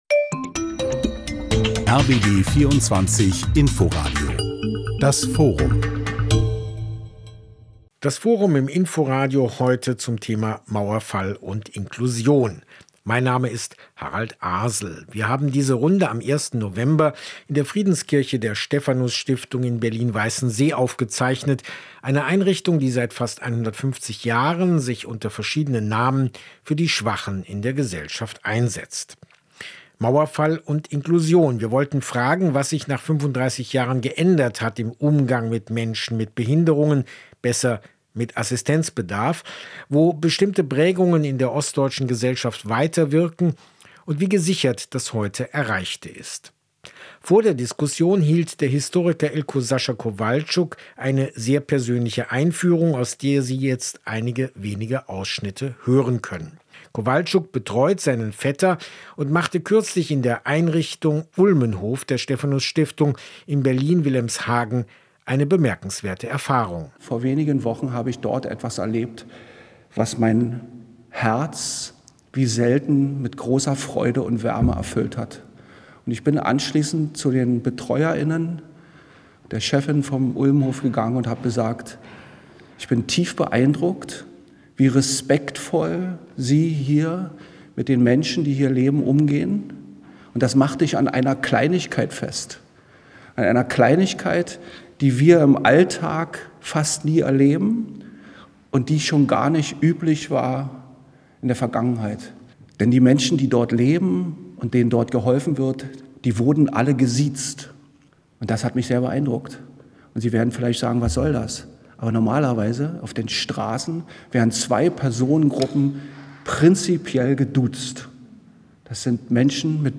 disktutiert mit seinen Gästen in der Stephanus Stiftung in Berlin.